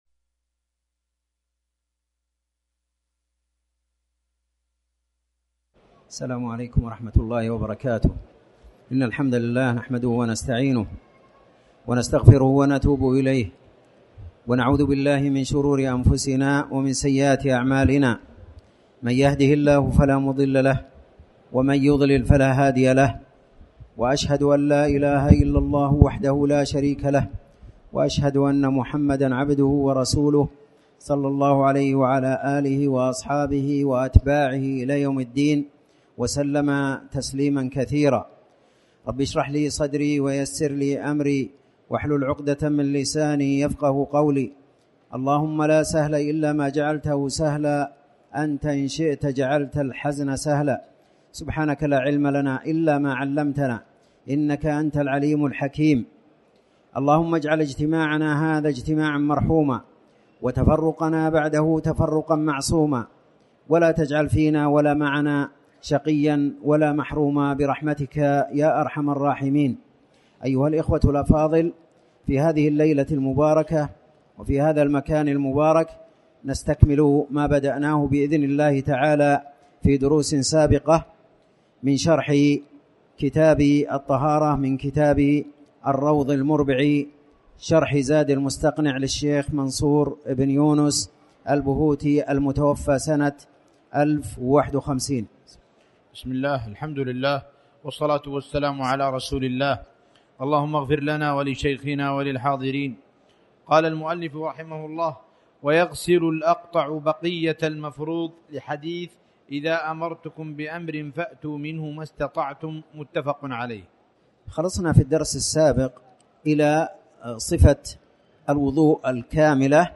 تاريخ النشر ٧ شعبان ١٤٣٩ هـ المكان: المسجد الحرام الشيخ